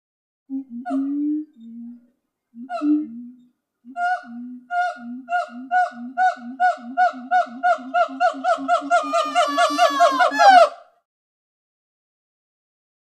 Gibbon Call. Two Gibbons Call With Low Pitched Hoots And High Yelps. Close Perspective.